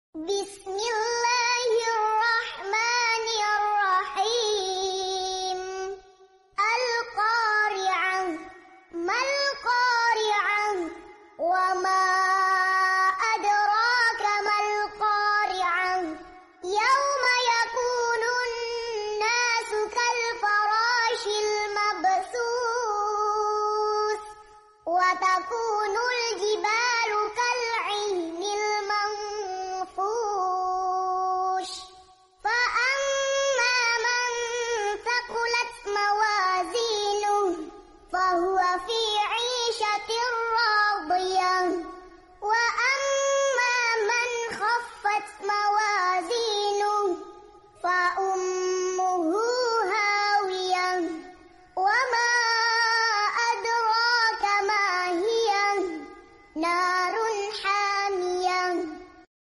Ngaji Murottal Anak Juz 30 Metode Ummi